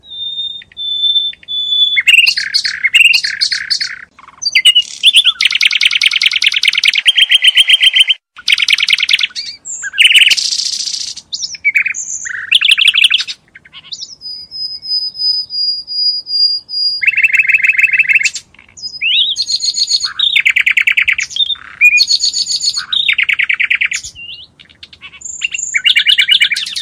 Vissla, Android, Naturljud